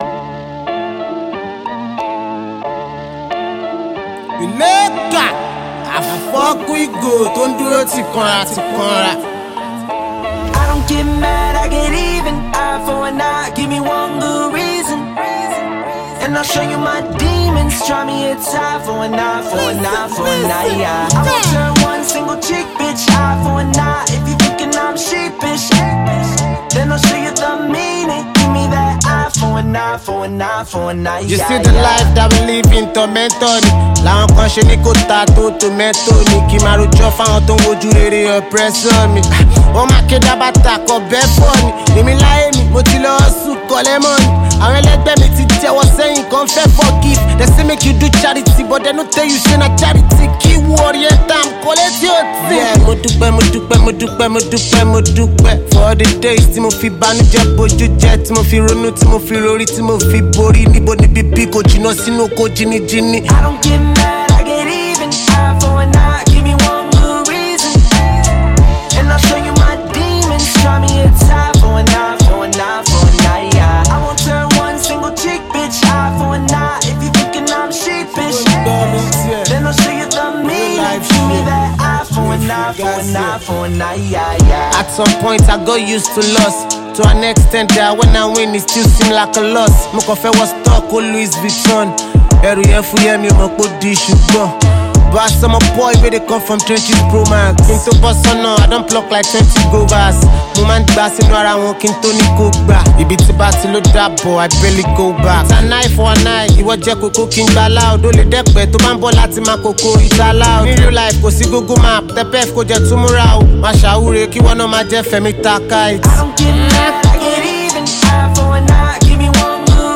Well renowned Nigerian artist and performer
thrilling new gbedu song